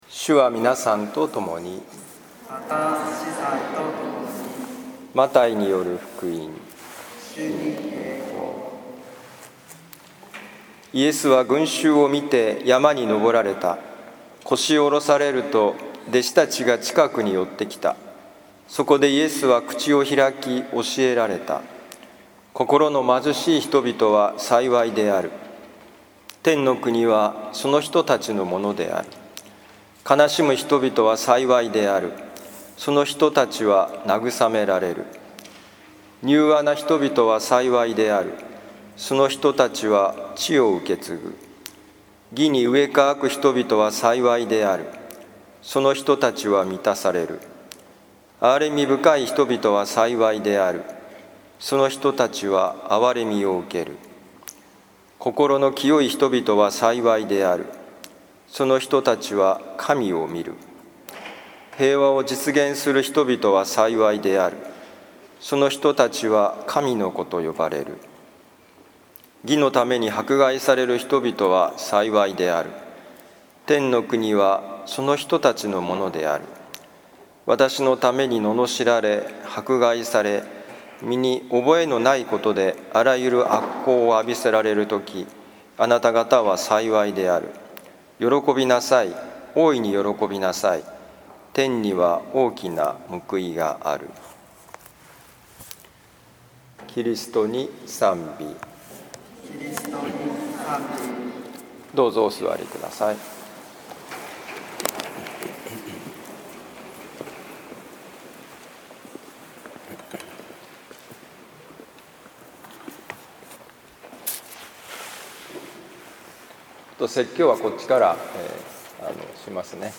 マタイ福音書5章1-12a節「大丈夫だよ」2020年11月1日諸聖人の祭日(年間第31主日)初聖体のミサ 聖イグナチオ教会
マタイ福音書5章1-12a節「大丈夫だよ」2020年11月1日諸聖人の祭日(年間第31主日)初聖体のミサ 聖イグナチオ教会 今日の福音書朗読とお説教の聞きどころ 今日は初聖体のミサなので、子どもにお話しされています 「貧しい人」はどんな人でしょうか？